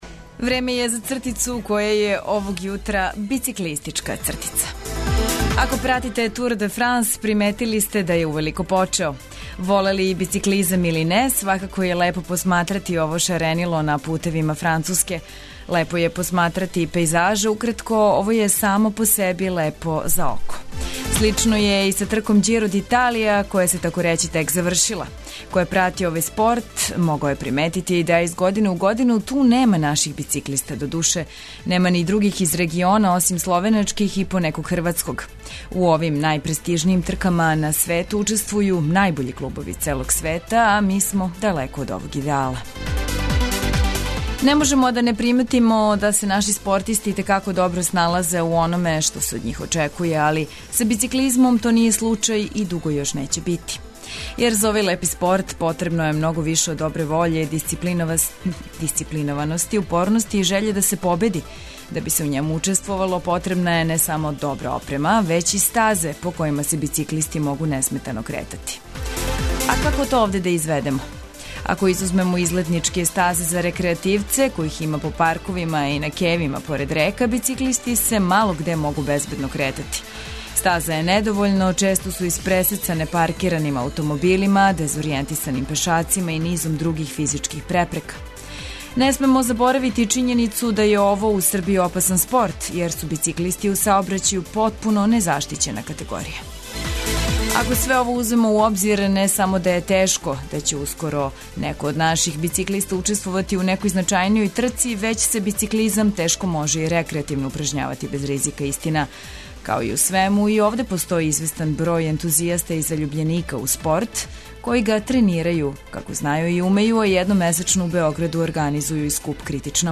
Музика је важан део Устанка па се трудимо да вас ујутру размрдамо полетним ритмовима али и подсетимо на неке старе композиције које желимо да отргнемо од заборава.